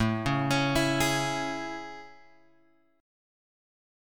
AM7 chord {5 4 x 6 5 4} chord